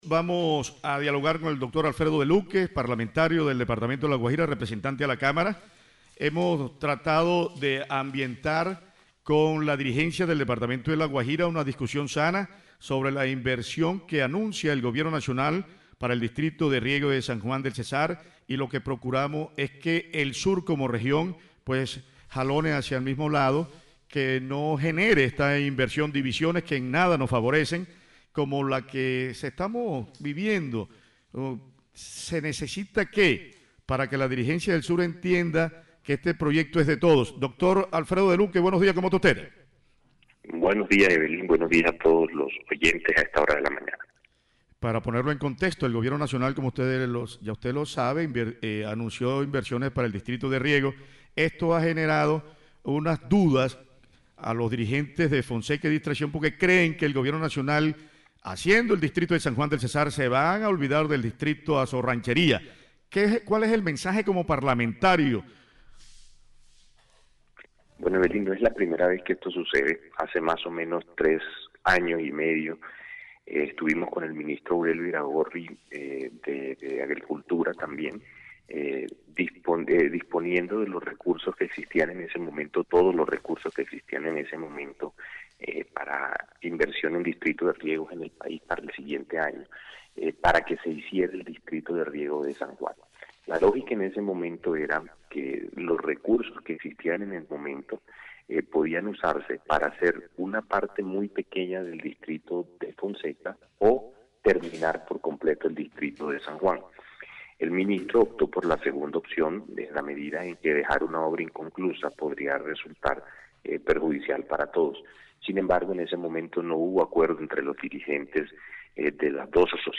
VOZ-HR-A-LA-CAMARA-ALFREDO-DELUQUE-SOBRE-TEMA-DISTRITO-DE-RIEGO-SAN-JUAN.mp3